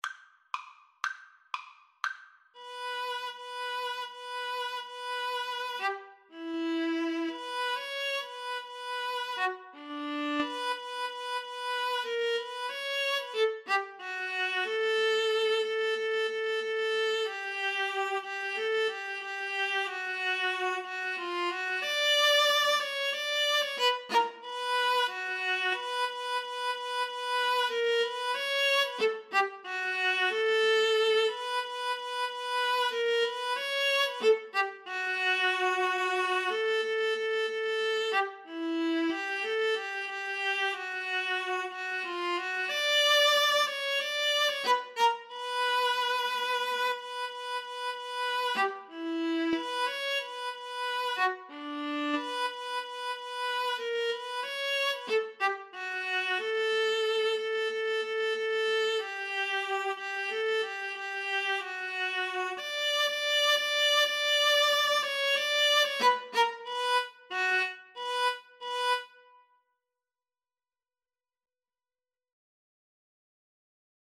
Steadily (first time) =c.60